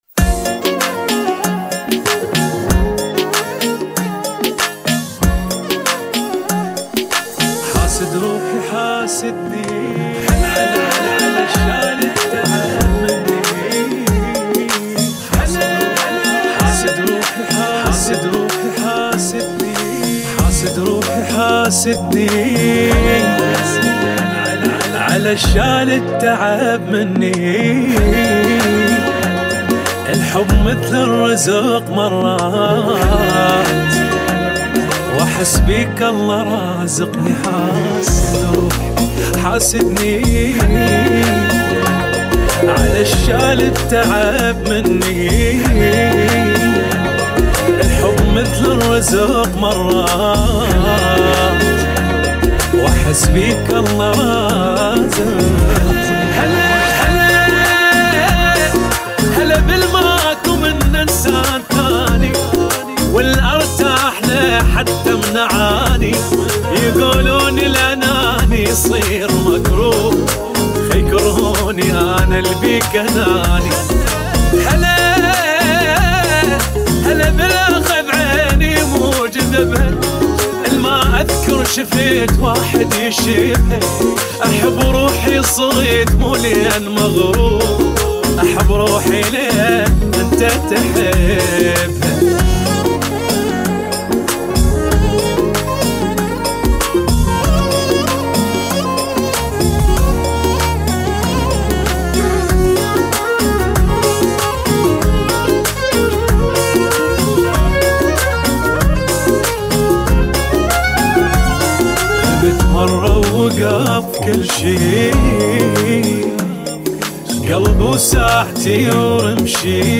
95 bpm